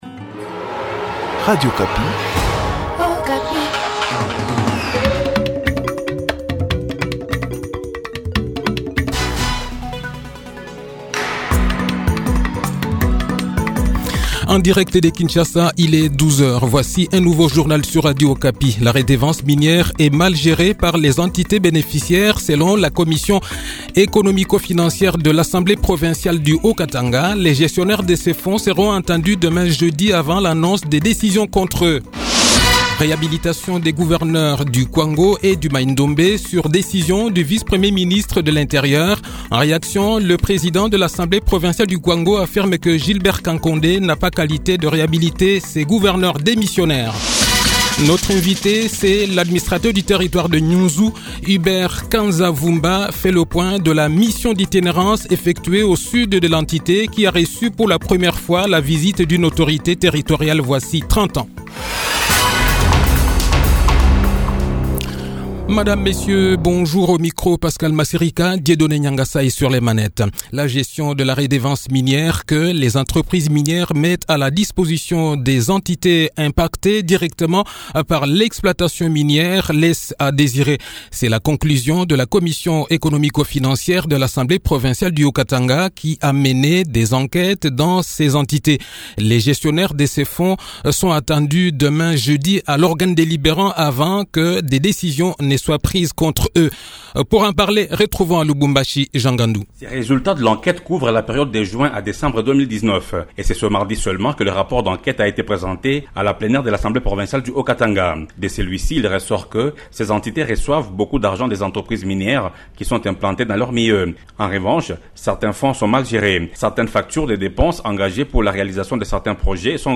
Le journal-Français-Midi
Le journal de 12 h, 4 Novembre 2020